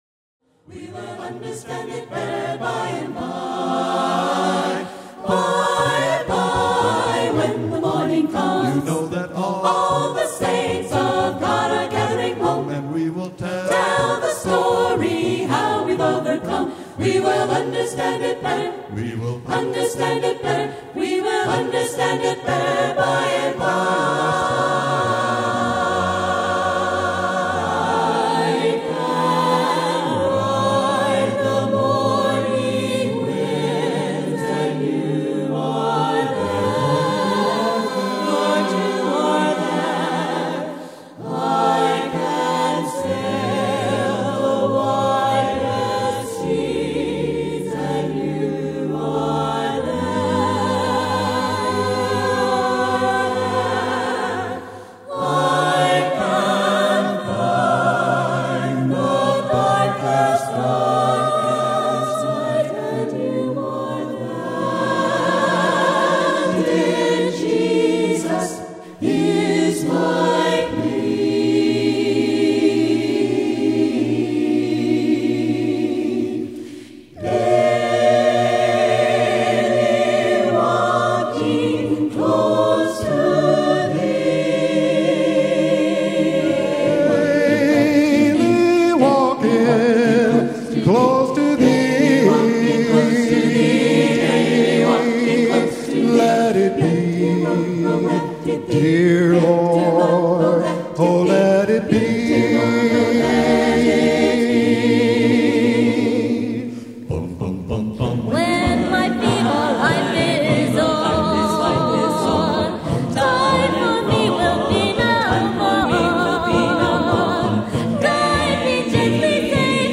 Sound sample from current group at 30 year reunion. Email The Sweet Expressions Austin Christian A Cappella Homepage || Picture and Bio || Who are we?